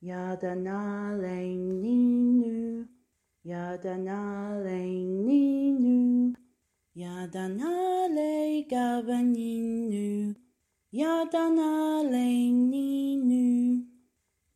Download a printable pdf file of the words to “Your Day”, a Gamilaraay/Yuwaalaraay version of the “Happy Birthday” song...
Yaadha (day) rhymes with father .
Nhalay (this) rhymes with archway.